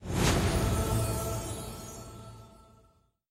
SE_SYS_Leveup.wav